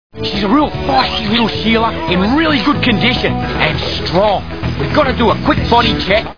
Crocodile Hunter TV Show Sound Bites